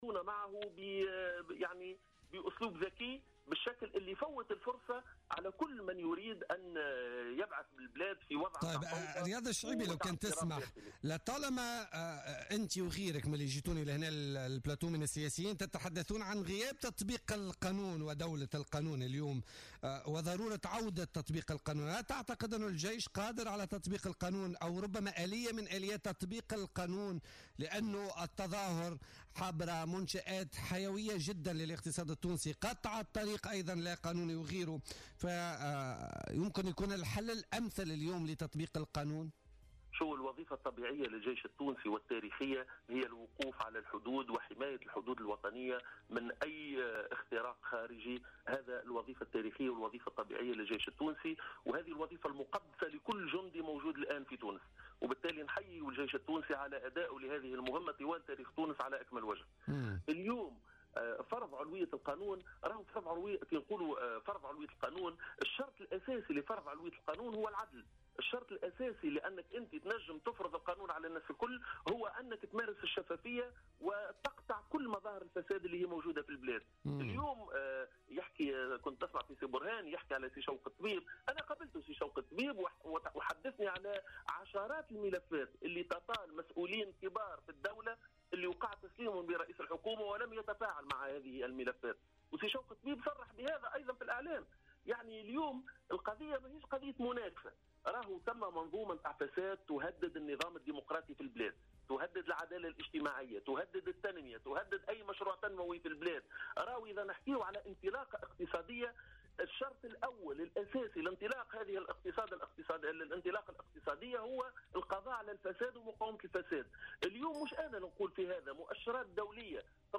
قال النائب عن حركة افاق تونس حافظ الزواري في مداخلة له في بولتيكا اليوم الأربعاء 10 ماي 2017 إن خطاب الباجي قايد السبسي تحدث في مجمله على مصالحة بين الجميع معتبرا أن المصالحة أمر ايجابي في مجملها ولكنها لاتحدث دون محاسبة وفق قوله.